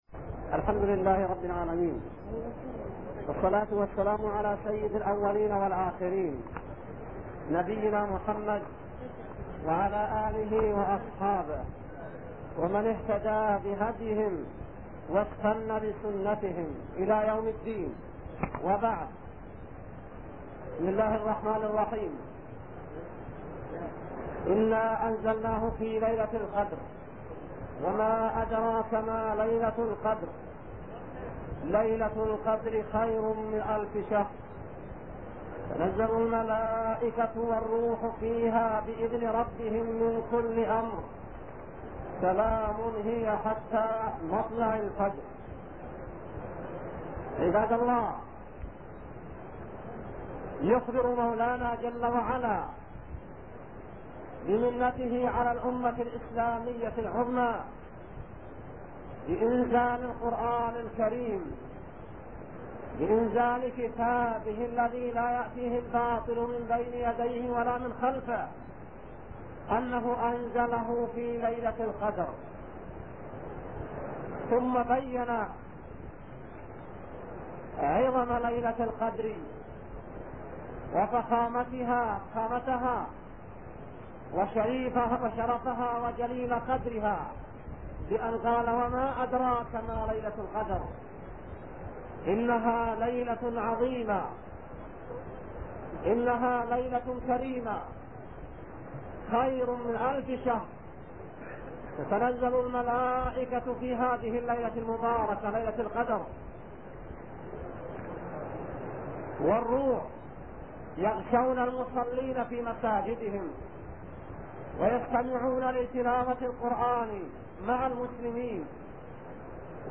مواعظ وفتاوى لفضيلة الشيخ صالح اللحيدان